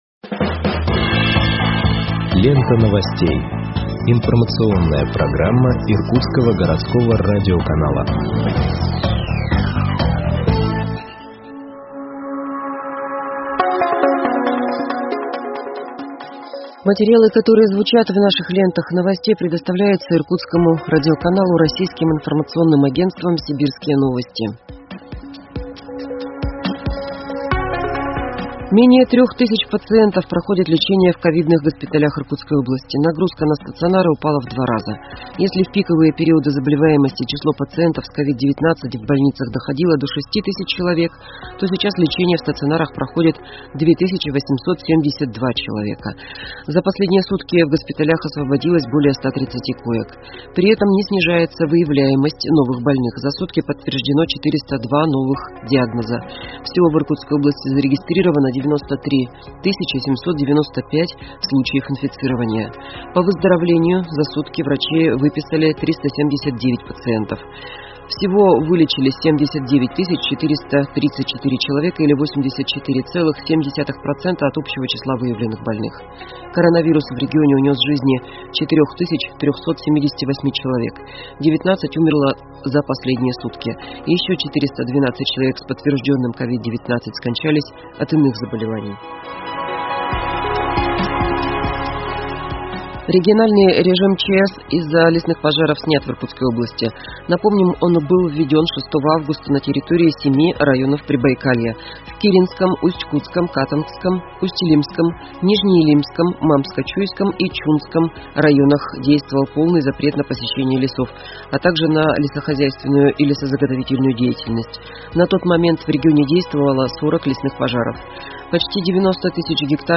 Выпуск новостей в подкастах газеты Иркутск от 20.08.2021 № 2